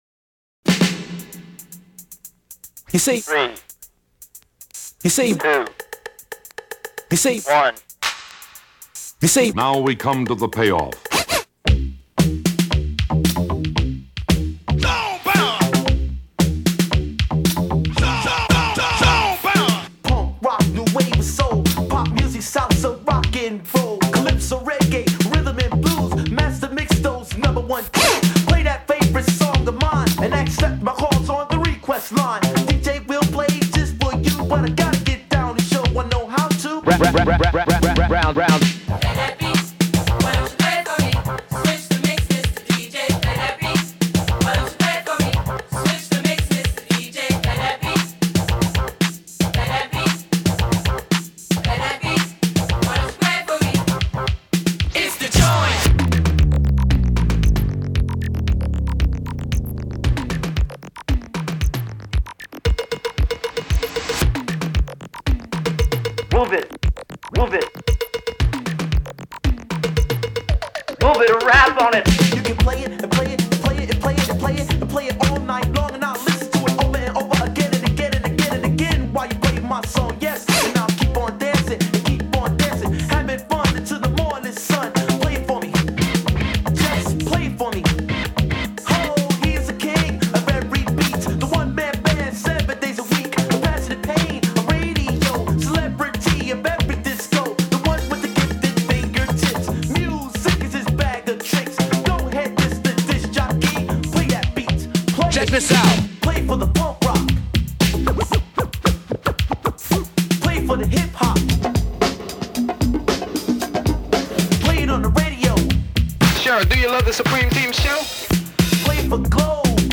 Collage Old-school Hip-Hop par un maître du genre.